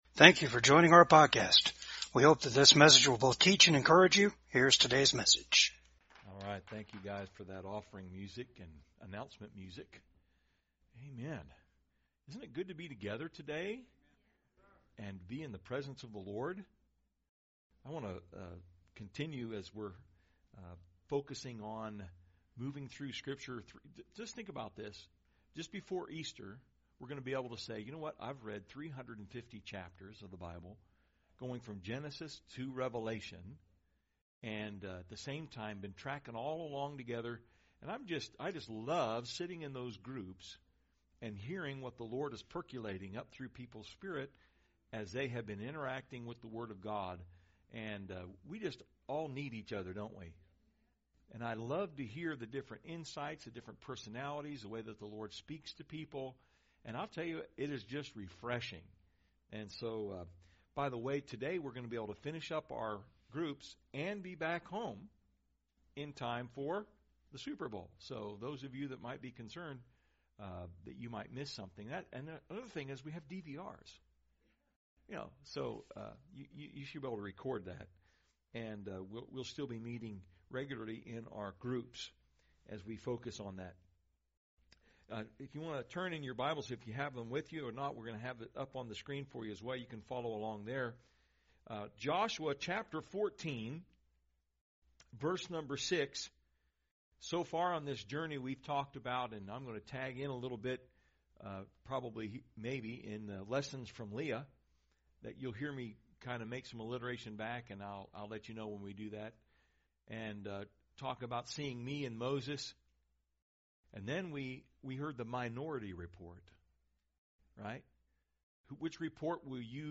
Joshua 14:6-15 Service Type: VCAG SUNDAY SERVICE IT'S AMAZING WHAT HAPPENS WHEN PEOPLE CO-OPERATE WITH GOD.